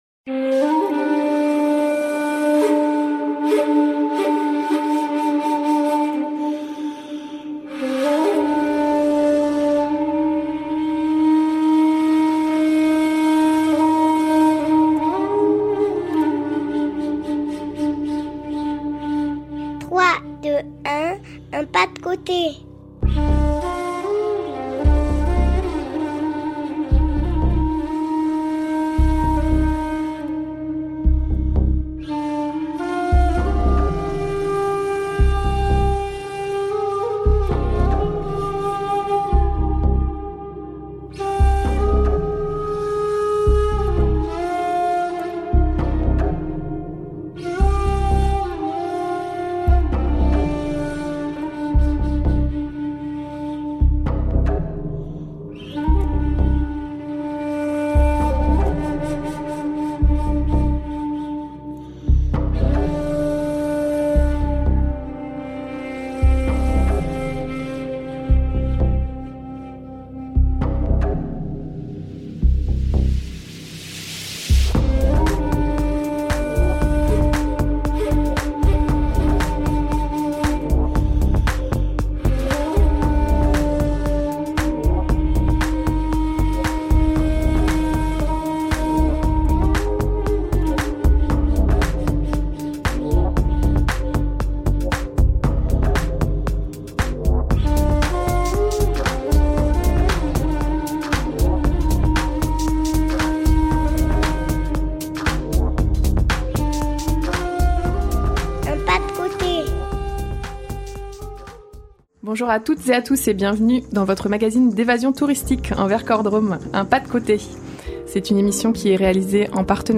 Votre magazine d’évasion touristique en Vercors-Drôme _ Édition Février 2024